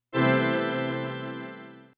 an unobtrusive, bland, scene separator.
Stingers.